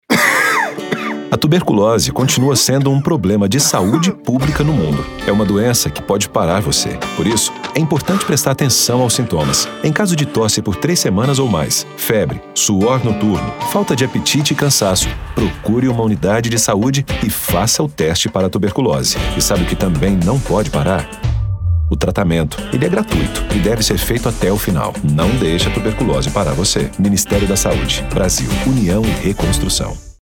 Áudio - Spot 30seg - Campanha Nacional de Tuberculose - 1,1mb .mp3